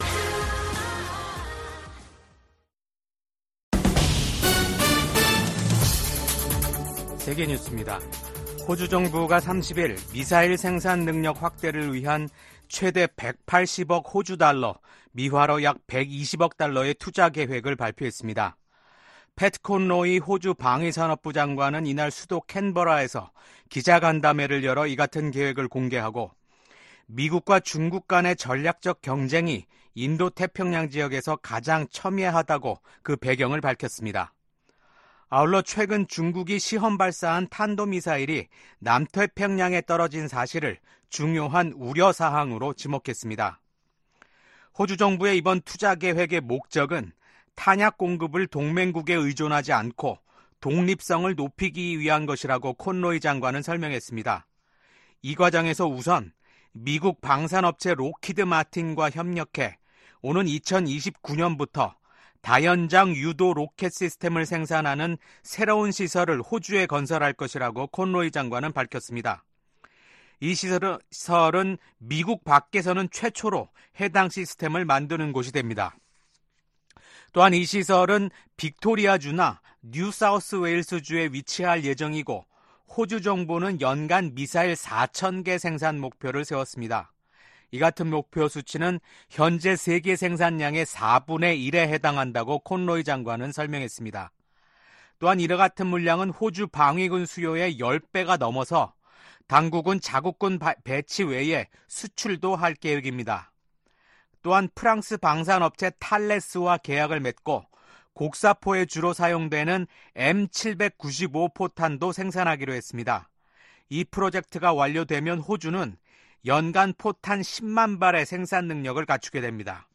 VOA 한국어 아침 뉴스 프로그램 '워싱턴 뉴스 광장' 2024년 10월 31일 방송입니다. 미국 국방부는 러시아에 파견된 북한군 일부가 이미 우크라이나에 가까운 러시아 쿠르스크에 주둔 중이라고 밝혔습니다. 윤석열 한국 대통령은 볼로디미르 젤렌스키 우크라이나 대통령과 쥐스탱 트뤼도 캐나다 총리와 연이어 통화를 하고 북한의 러시아 파병에 대한 공동 대응 의지를 밝혔습니다.